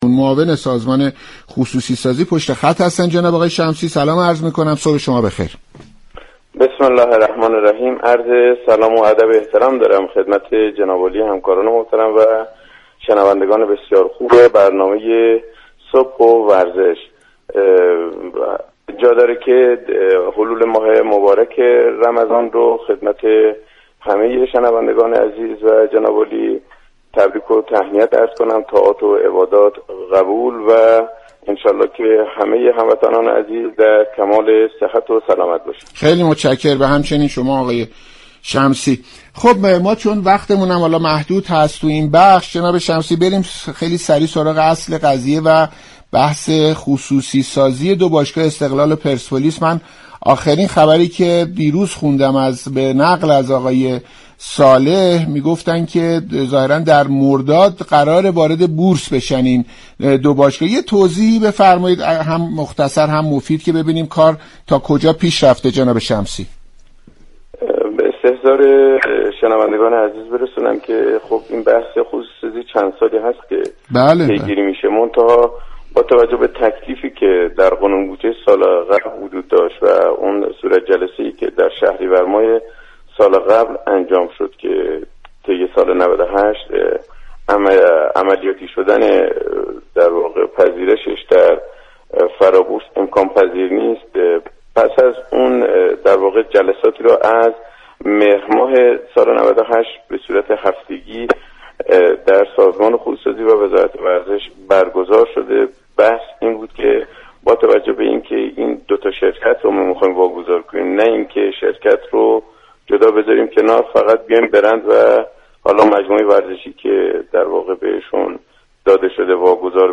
ابوالقاسم شمسی، معاون سازمان خصوصی سازی در گفتگو با برنامه